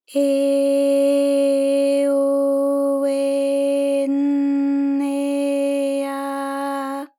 ALYS-DB-001-JPN - First Japanese UTAU vocal library of ALYS.
e_e_o_e_n_e_a.wav